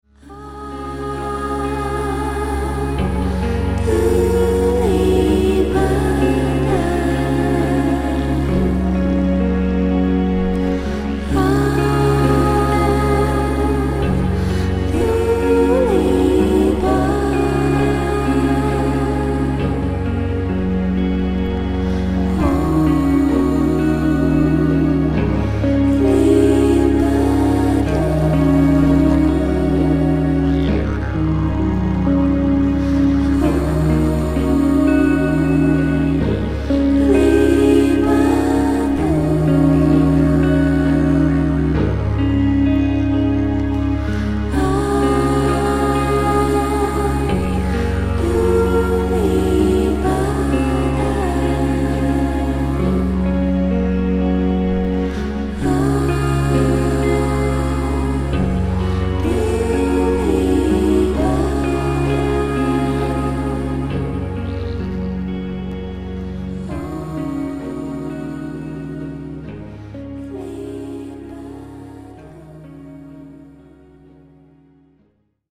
Meditationsmusik anbieten.